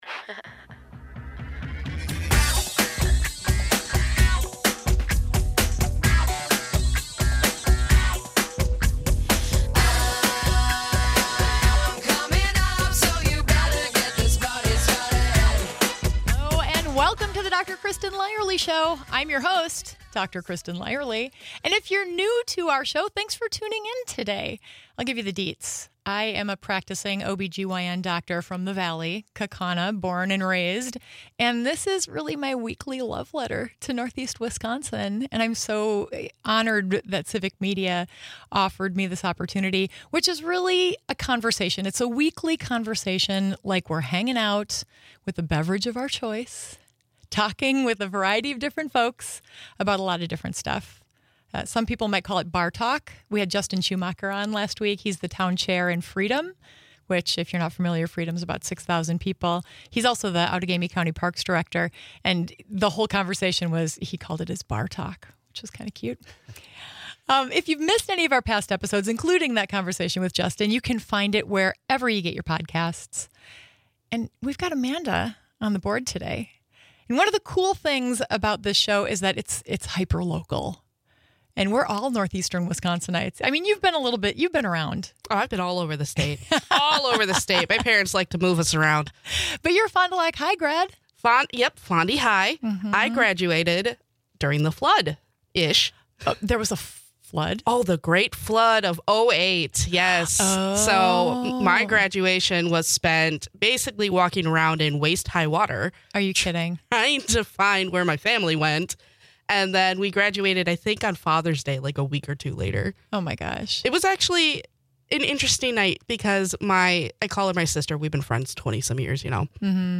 Guests: Eric Genrich